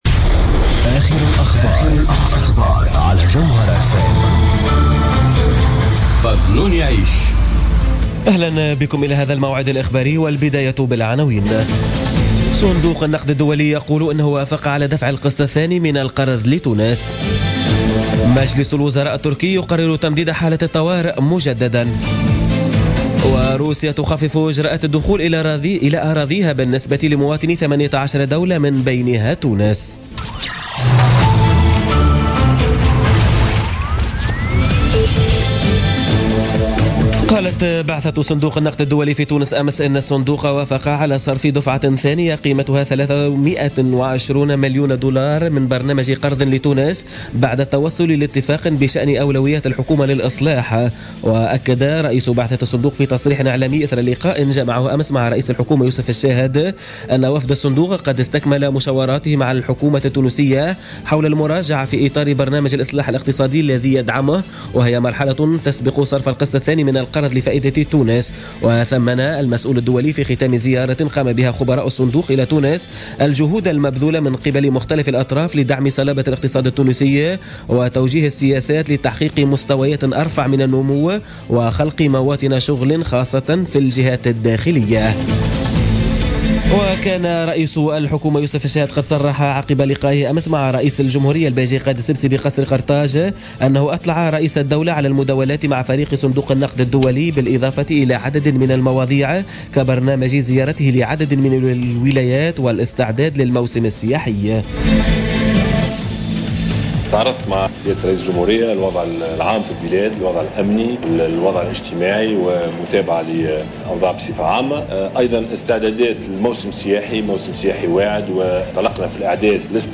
نشرة أخبار منتصف الليل ليوم الثلاثاء 18 أفريل 2017